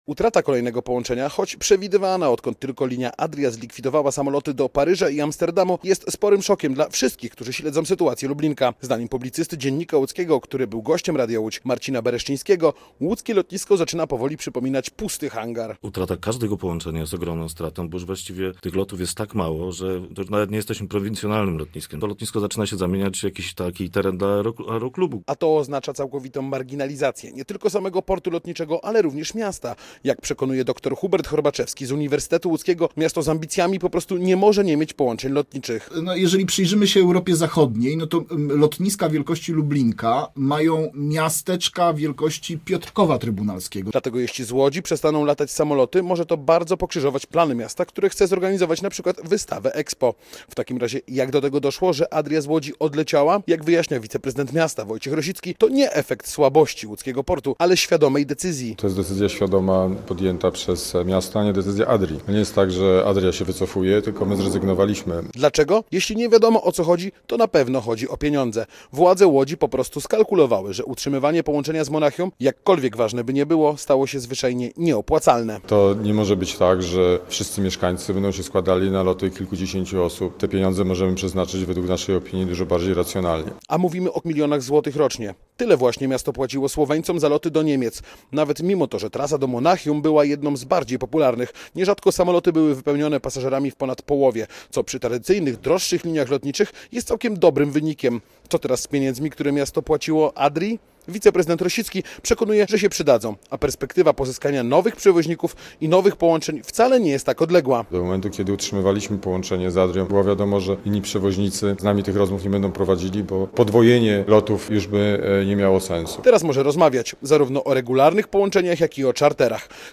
Posłuchaj relacji: Nazwa Plik Autor Co dalej z lotniskiem? audio (m4a) audio (oga) CZYTAJ TAKŻE: Łódź traci kolejne połączenie.